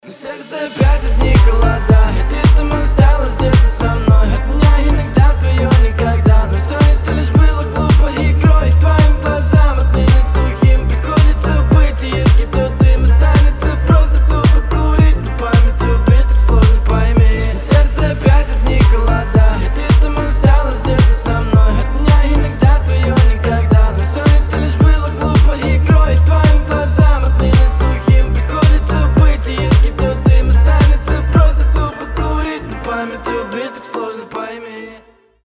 • Качество: 320, Stereo
мужской голос
лирика
грустные